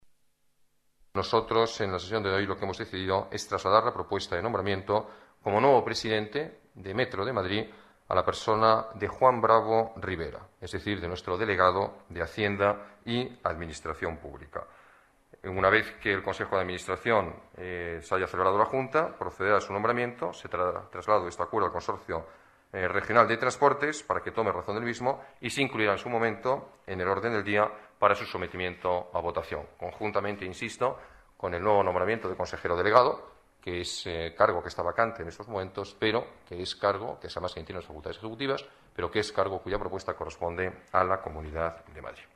Nueva ventana:Declaraciones alcalde de Madrid, Alberto Ruiz-Gallardón: propuesta Juan Bravo presidente Metro